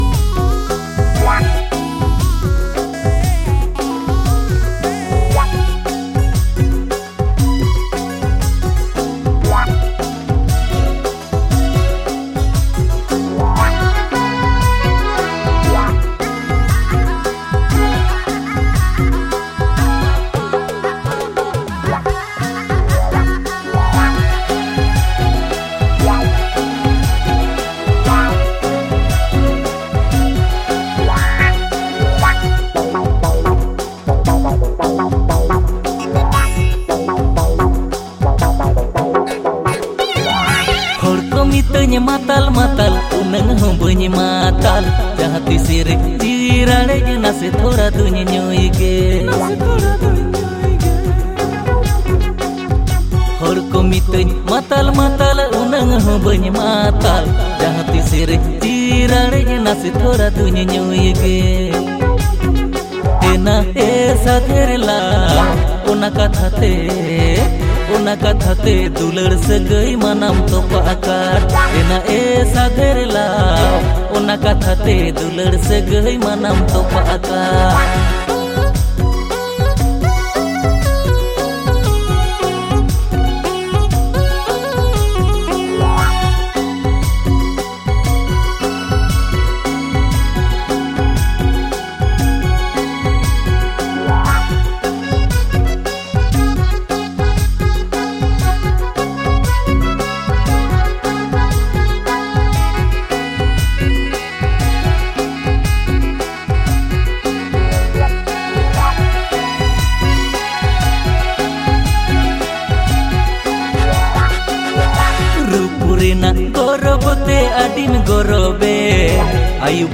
• Male Artist